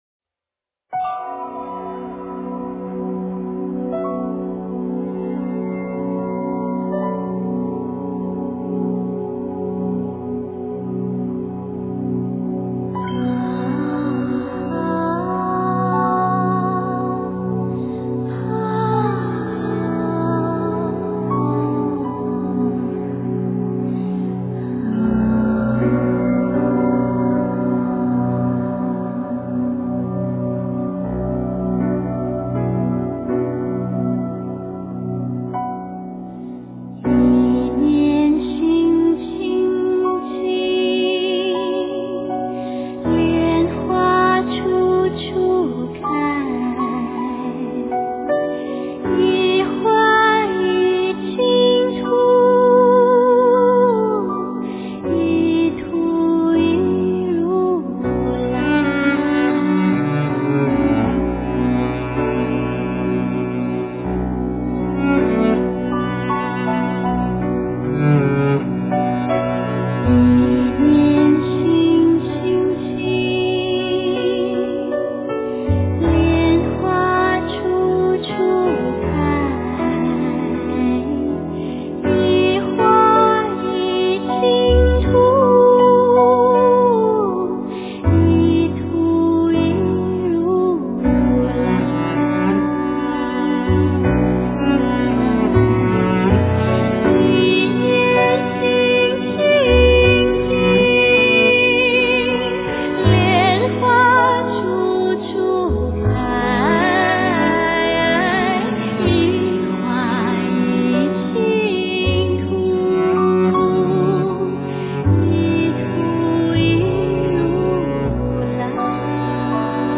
佛音
佛教音乐